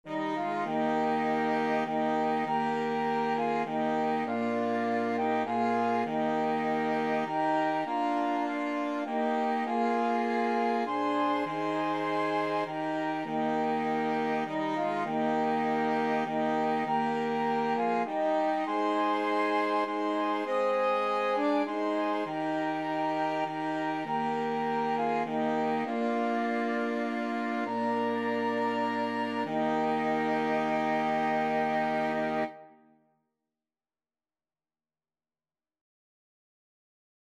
Christian Christian Flexible Mixed Ensemble - 4 Players Sheet Music Just as I Am, Without One Plea
Free Sheet music for Flexible Mixed Ensemble - 4 Players
FluteViolin
French Horn
Baritone Saxophone
6/4 (View more 6/4 Music)
Eb major (Sounding Pitch) (View more Eb major Music for Flexible Mixed Ensemble - 4 Players )
Classical (View more Classical Flexible Mixed Ensemble - 4 Players Music)